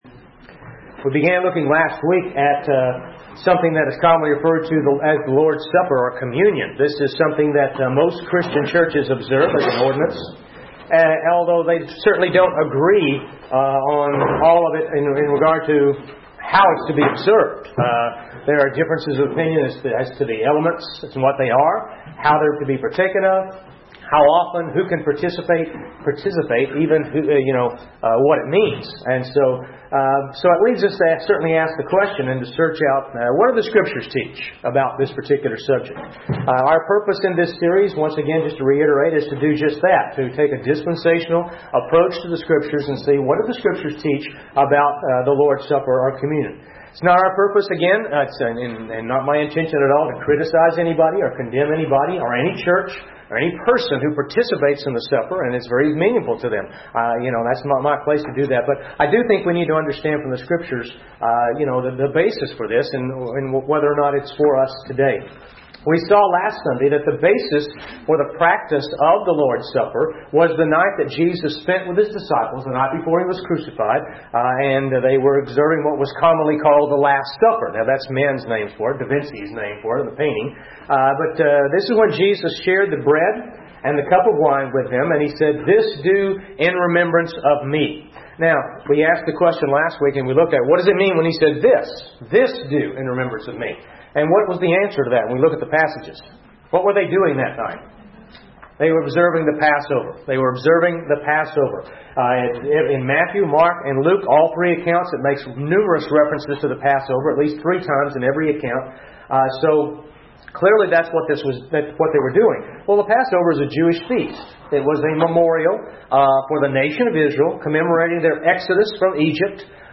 A message from the series "The Lord\'s Supper."